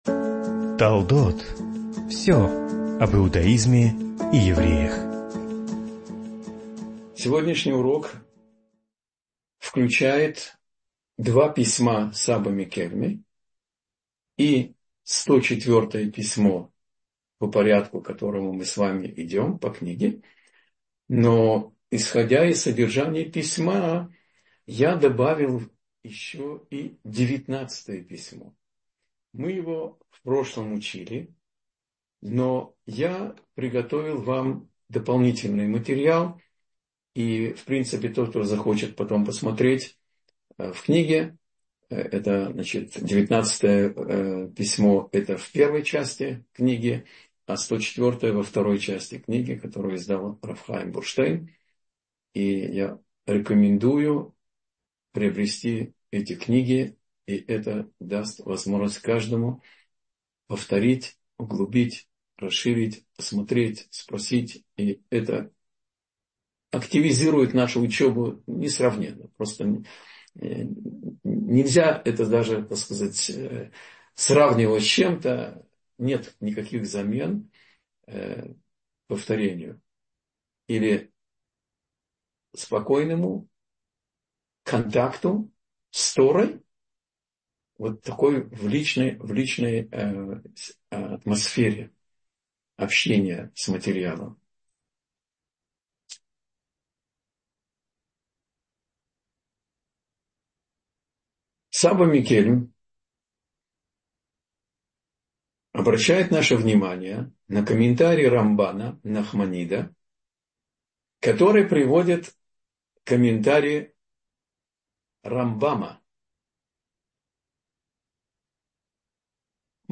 Заслуги отцов — слушать лекции раввинов онлайн | Еврейские аудиоуроки по теме «Мировоззрение» на Толдот.ру